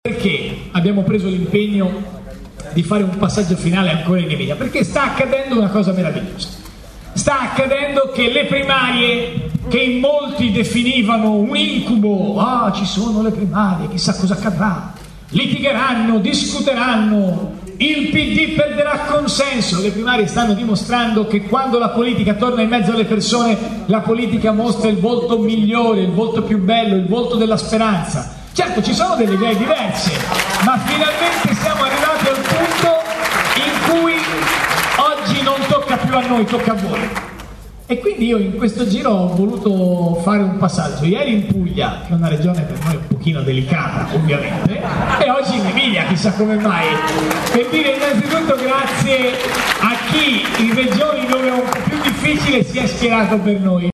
E’ stato un monologo lampo quello del candidato alle primarie del centrosinistra nella sua seconda visita bolognese. La sala del Baraccano era talmente colma che i vigili hanno dovuto chiedere ad alcune persone di uscire nel giardino.